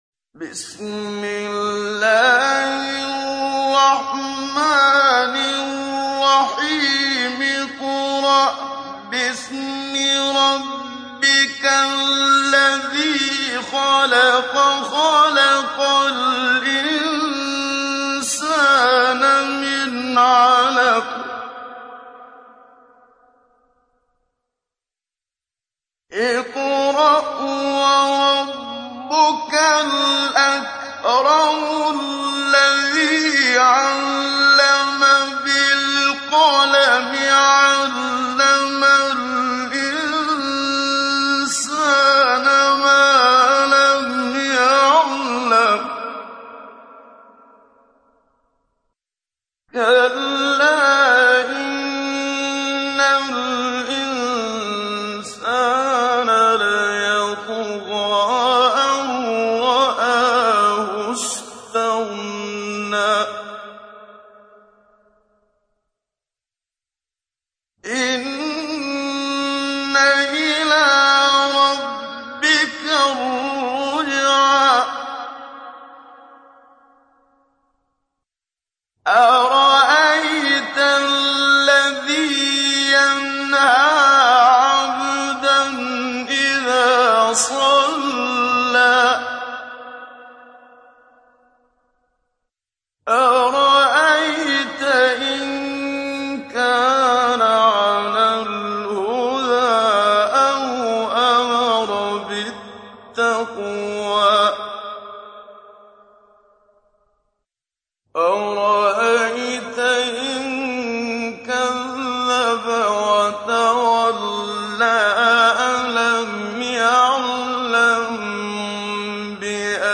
تحميل : 96. سورة العلق / القارئ محمد صديق المنشاوي / القرآن الكريم / موقع يا حسين